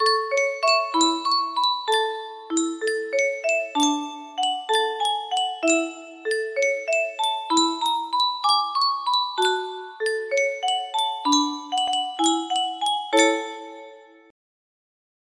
Yunsheng Spieluhr - Leise Rieselt der Schnee Y601 music box melody
Full range 60